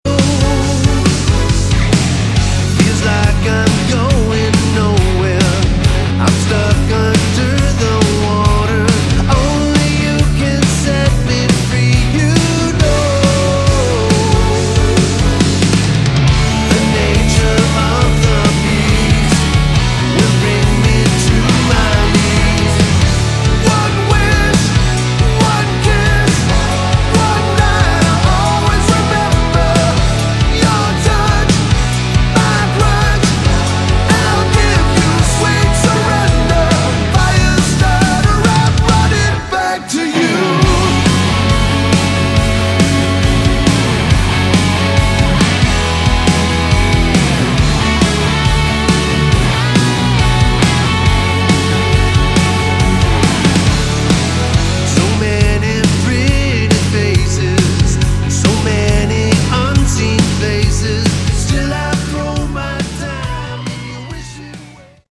Category: Melodic Rock
lead vocals, bass, keyboards
drums
backing vocals